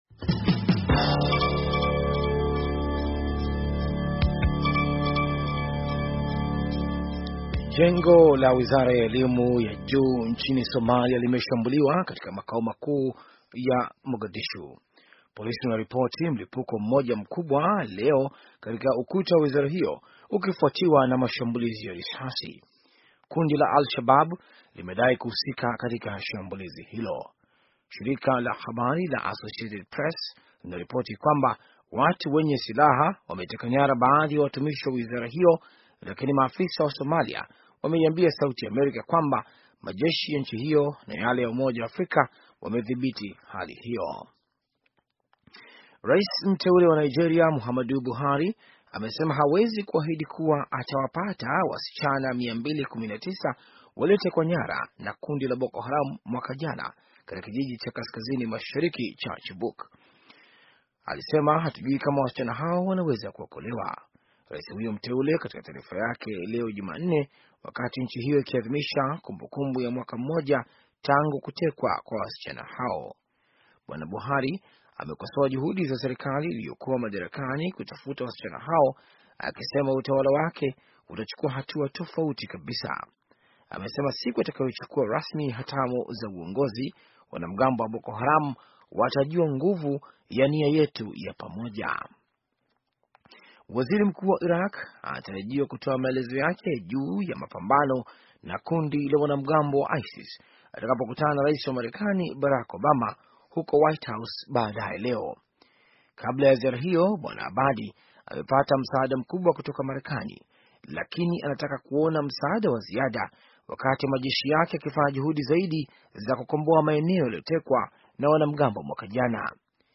Taarifa ya habari - 5:23